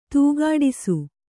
♪ tūgāḍisu